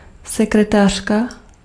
Ääntäminen
IPA : /ˈsɛk.ɹəˌtə.ɹi/ IPA : /ˈsɛk.ɹə.tɹi/